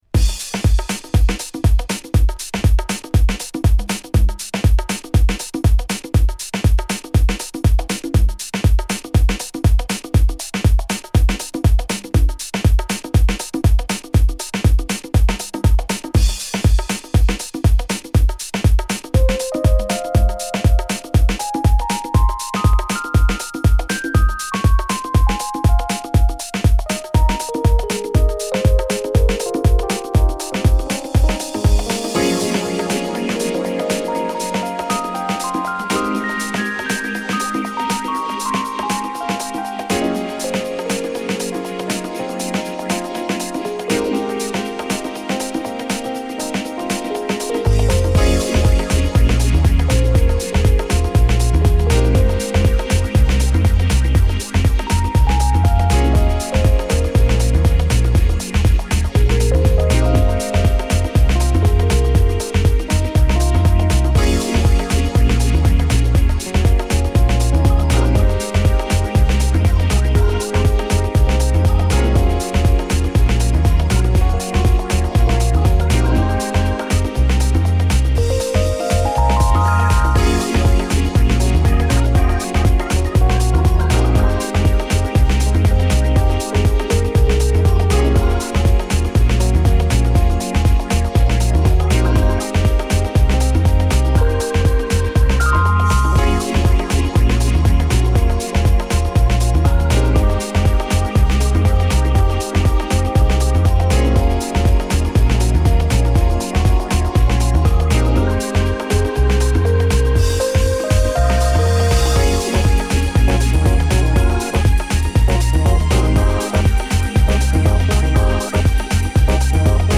＊試聴はB2→B1→Aです。